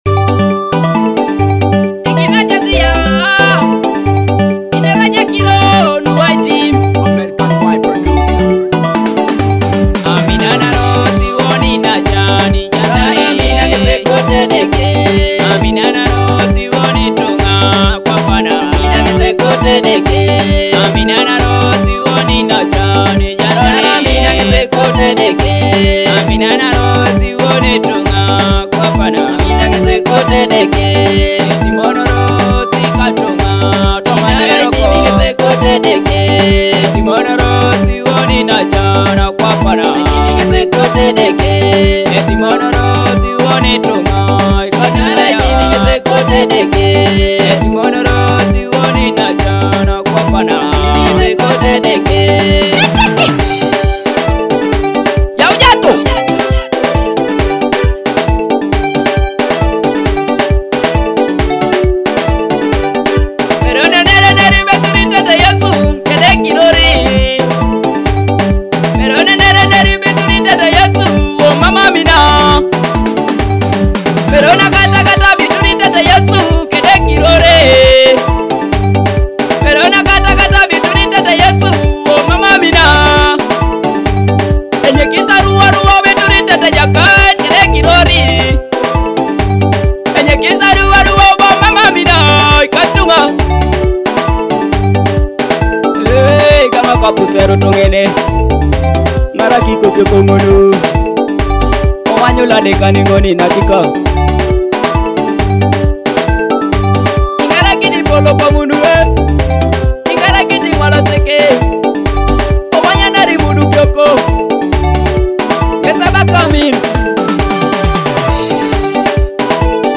With heartfelt lyrics and soulful melodies